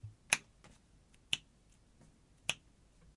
描述：轻弹3次